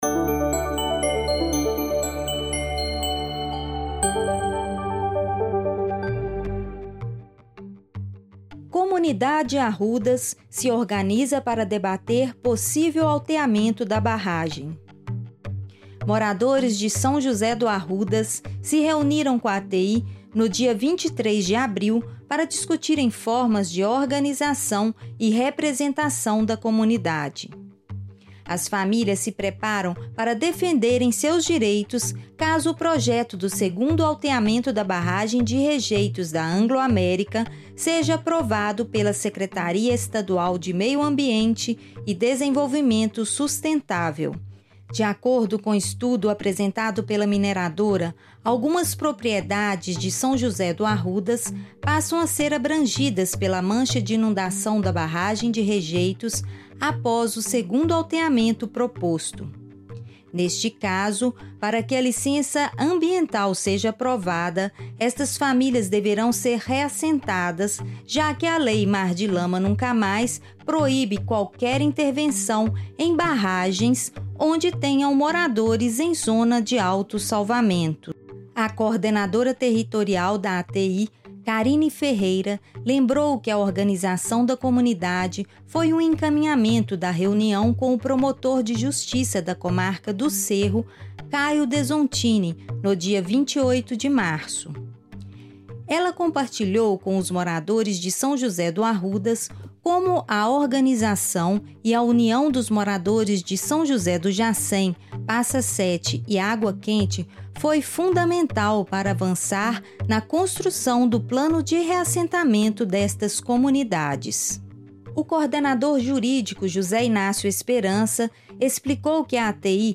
Audiorreportagem-Arrudas-Organizacao-Comunitaria.mp3